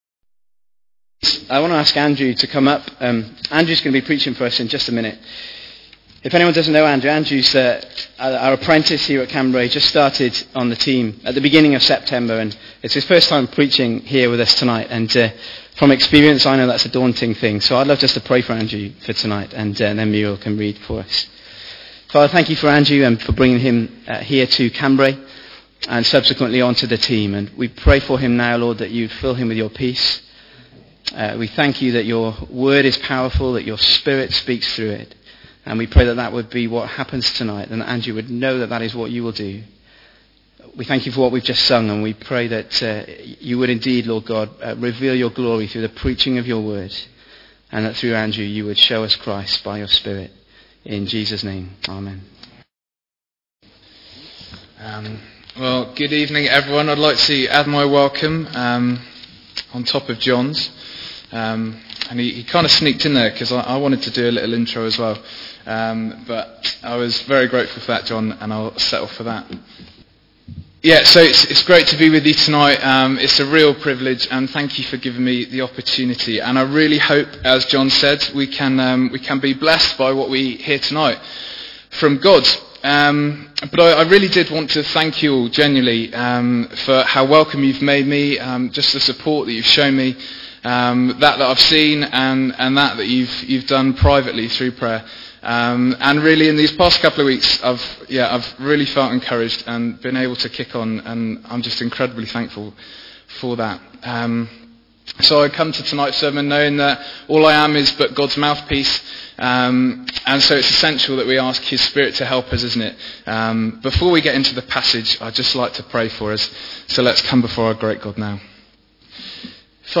Bible Text: Luke 6:43-49 | Preacher